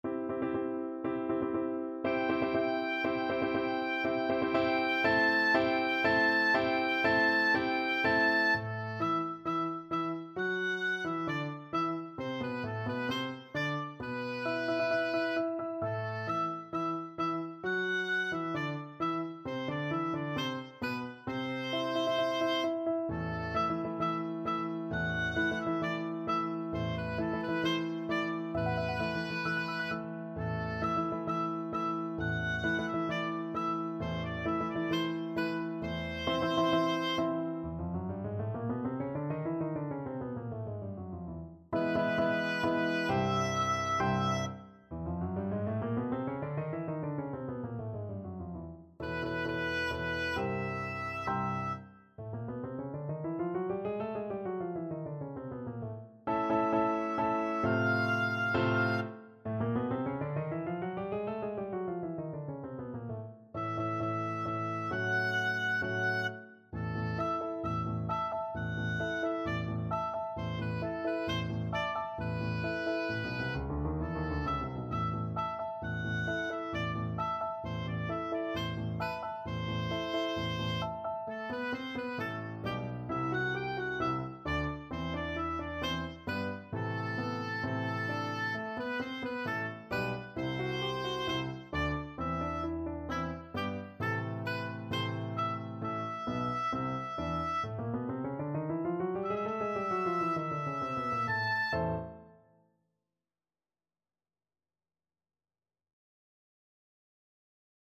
4/4 (View more 4/4 Music)
Allegro non troppo (View more music marked Allegro)
C major (Sounding Pitch) (View more C major Music for Oboe )
Oboe  (View more Easy Oboe Music)
Classical (View more Classical Oboe Music)